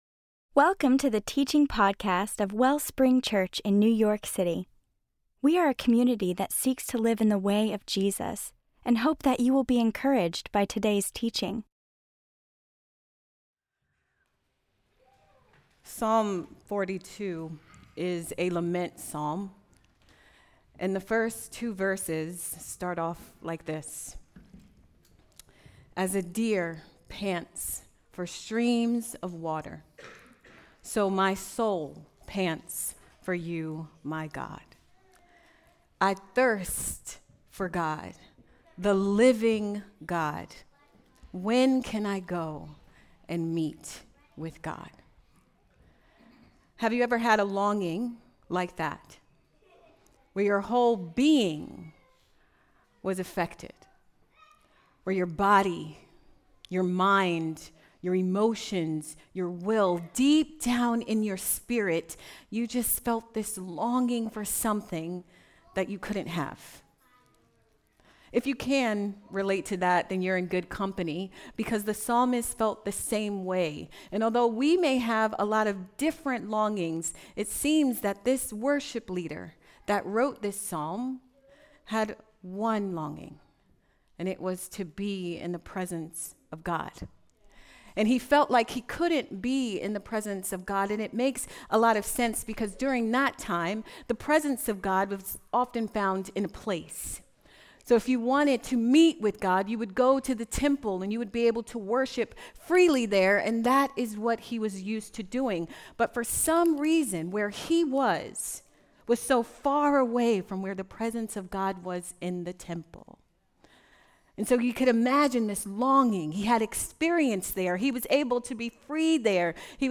CHRISTMAS CANDLELIGHT SERVICE 2025